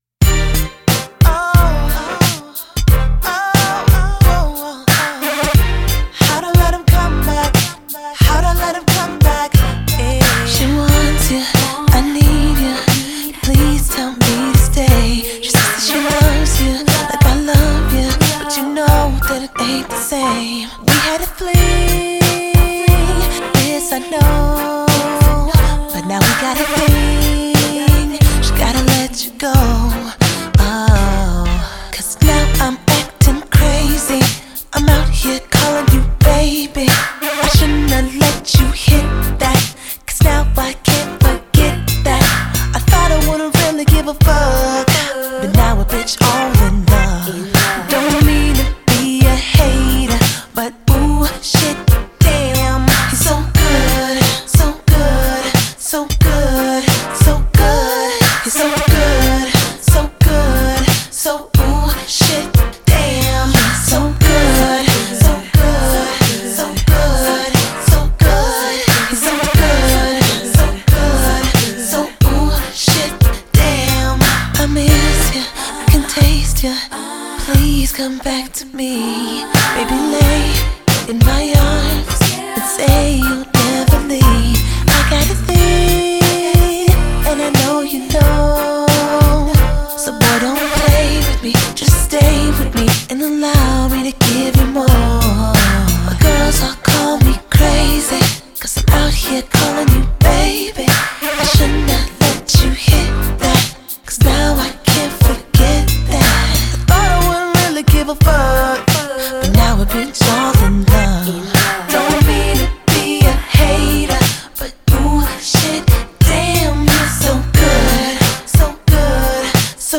音乐类型：R&B/Hip-Hop/Alternative
2009 debut from the all-girl R&B/Pop singing group.